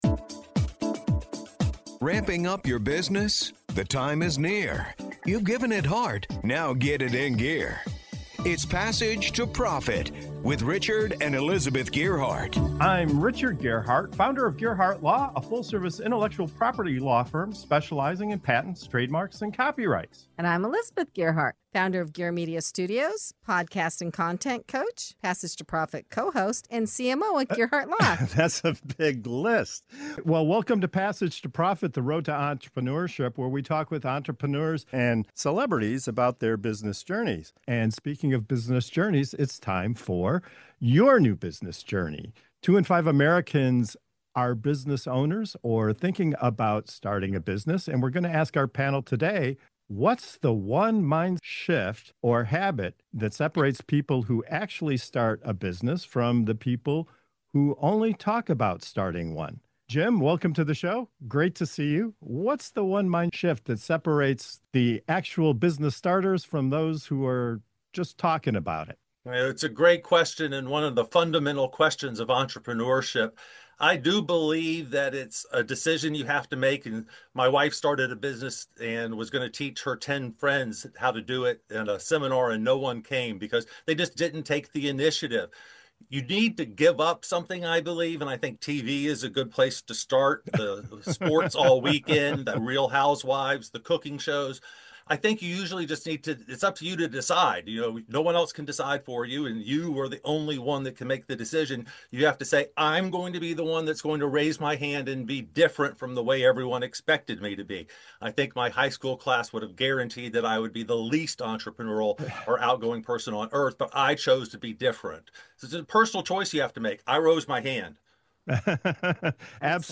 What truly separates aspiring entrepreneurs from those who actually take the leap? In this energizing panel discussion, seasoned founders and business leaders cut through the noise to reveal the mindset shift that turns ideas into action.